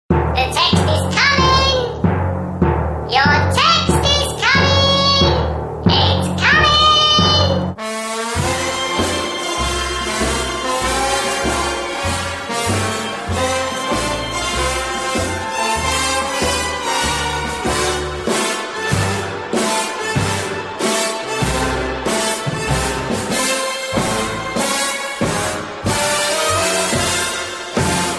Message Tones